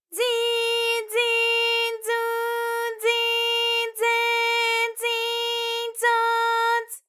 ALYS-DB-001-JPN - First Japanese UTAU vocal library of ALYS.
zi_zi_zu_zi_ze_zi_zo_z.wav